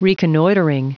Prononciation du mot reconnoitring en anglais (fichier audio)
Prononciation du mot : reconnoitring